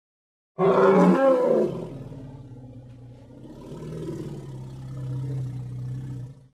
Звуки голоса древнего мамонта